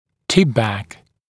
[‘tɪpbæk][‘типбэк]приводящий к дистальному наклону зуба